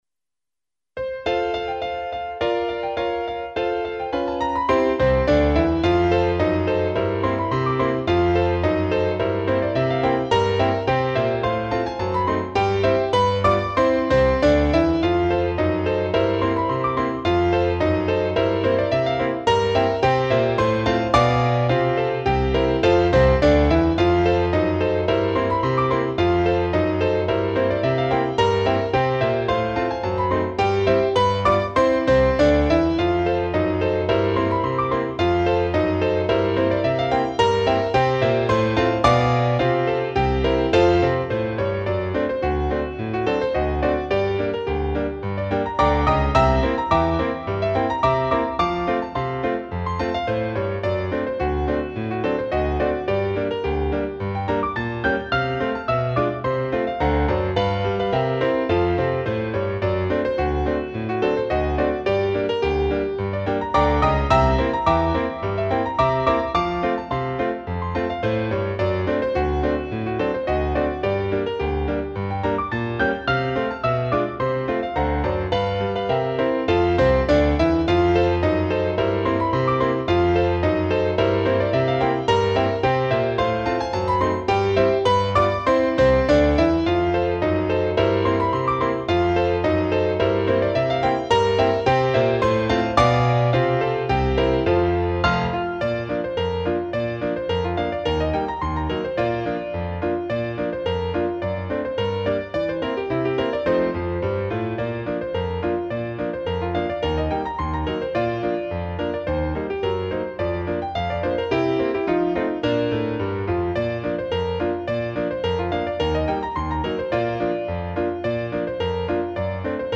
I made it with "Miroslav Philharmonik"
RAGTIME MUSIC